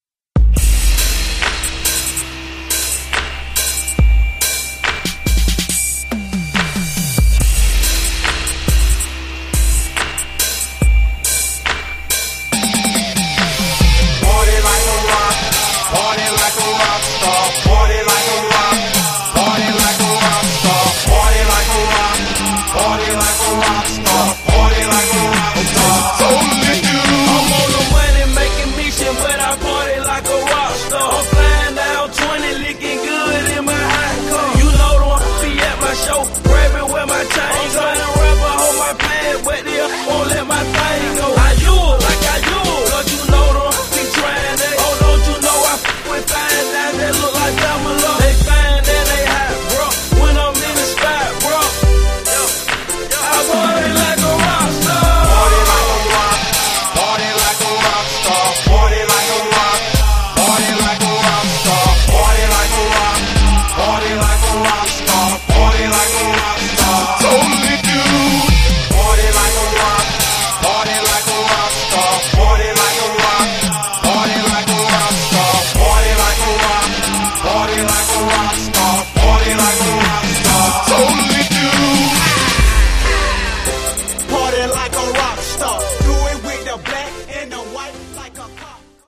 69 bpm
Clean Version